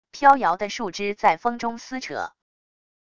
飘摇的树枝在风中撕扯wav音频